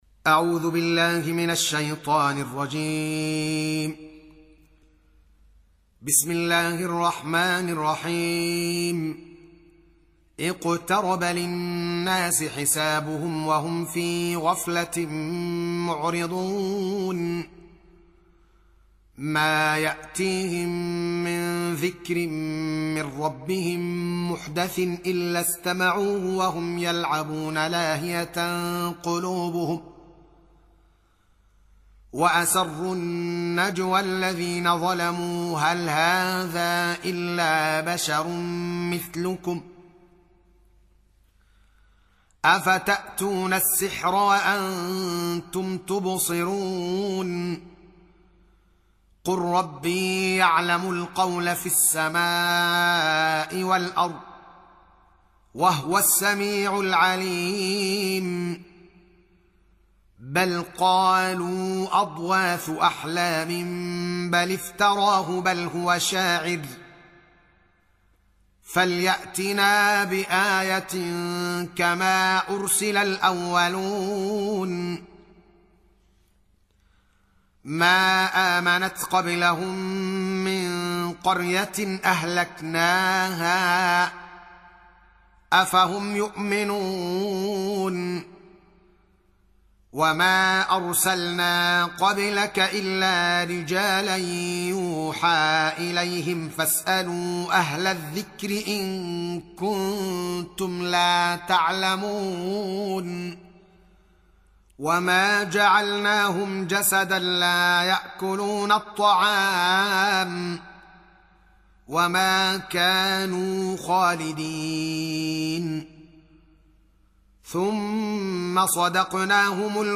Surah Al-Anbiy�' سورة الأنبياء Audio Quran Tarteel Recitation
حفص عن عاصم Hafs for Assem